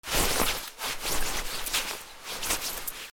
布ずれ 激しく
/ J｜フォーリー(布ずれ・動作) / J-05 ｜布ずれ
『シュルシュル』